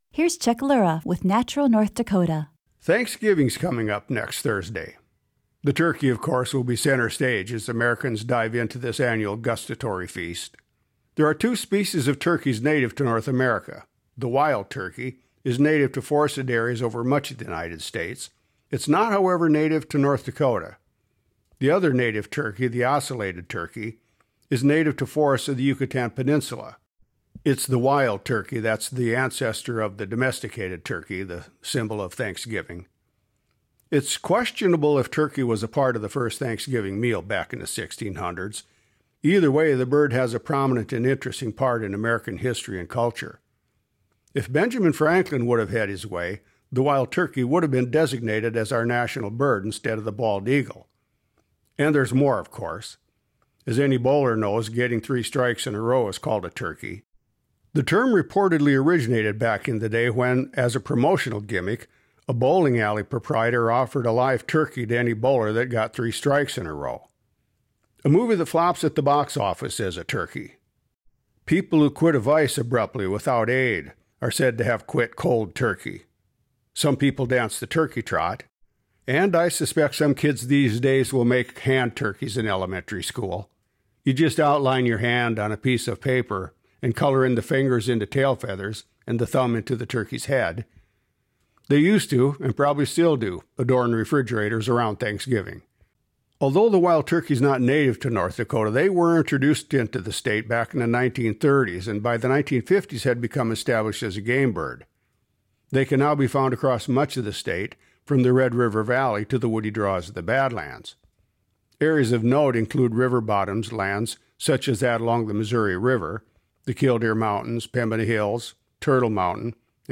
nov23-wildturkey.mp3